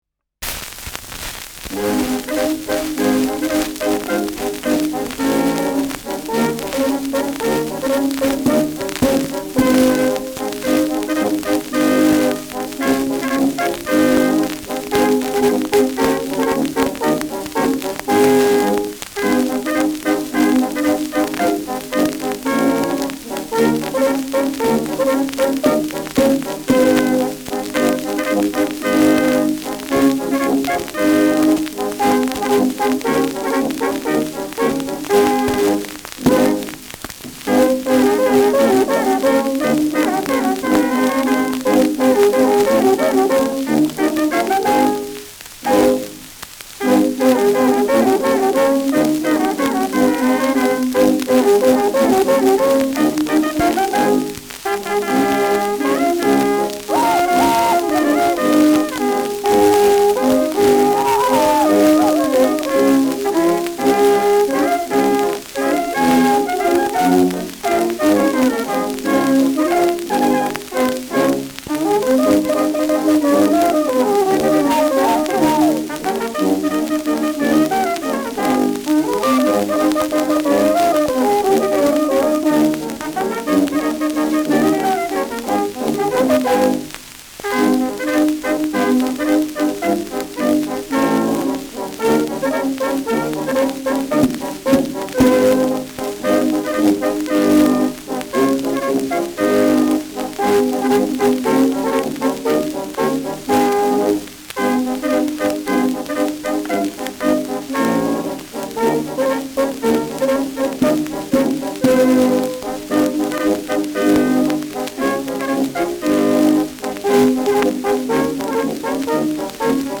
Schellackplatte
präsentes Rauschen : präsentes Knistern : leichtes Leiern
[München] (Aufnahmeort)